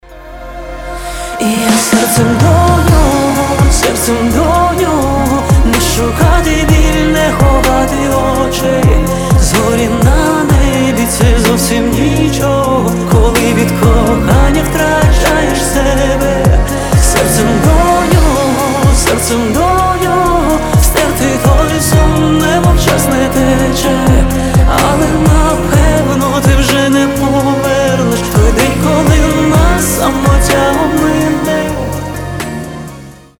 поп , романтические